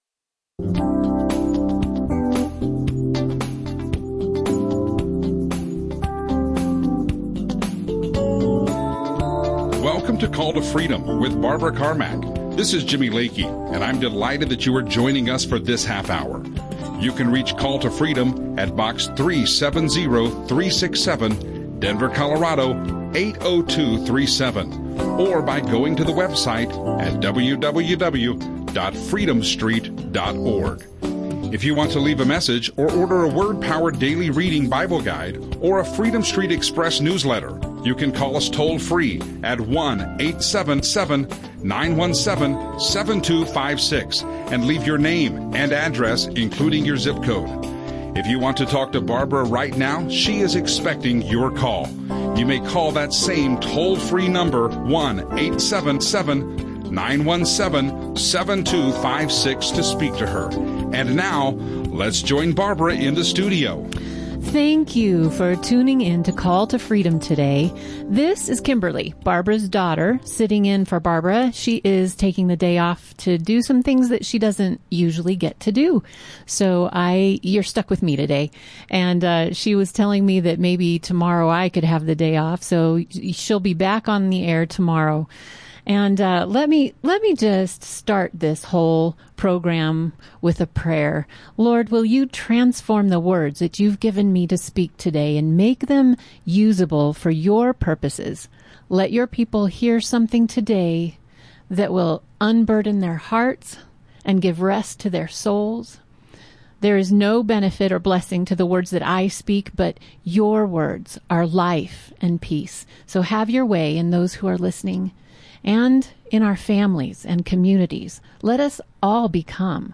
Audio teachings
Christian radio